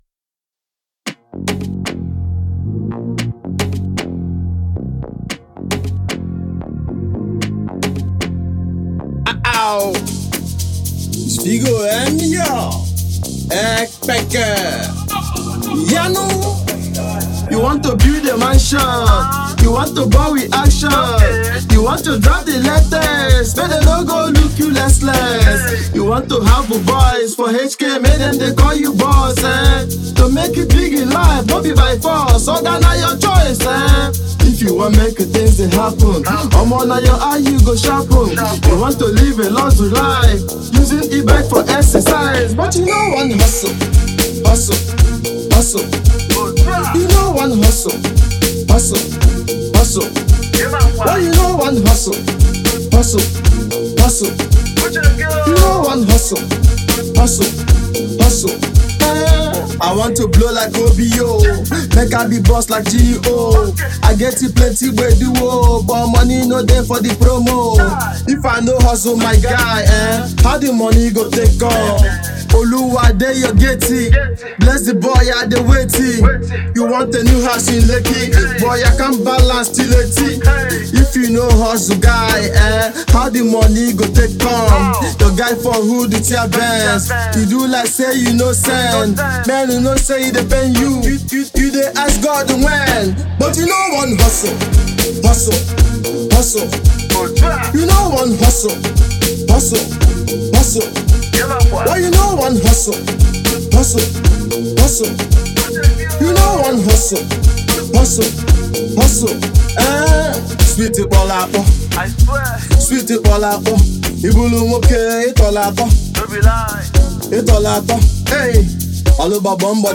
raw voice